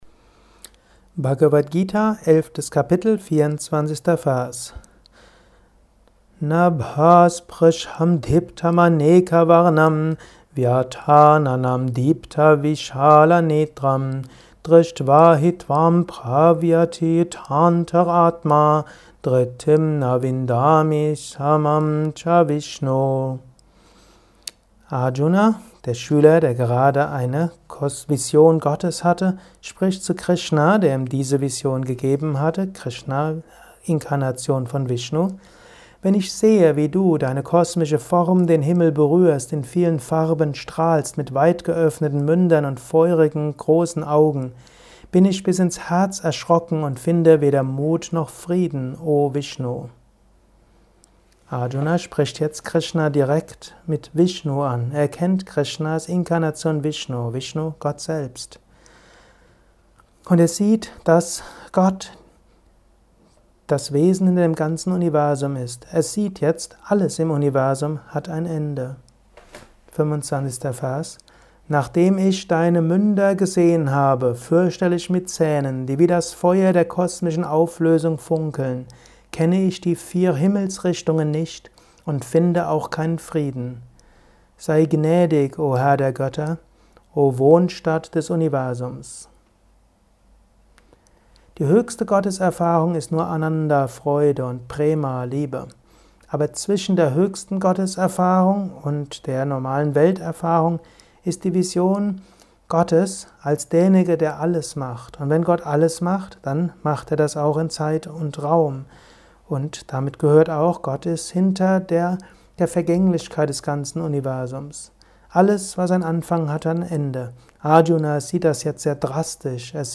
Dies ist ein kurzer Kommentar als Inspiration für den heutigen Tag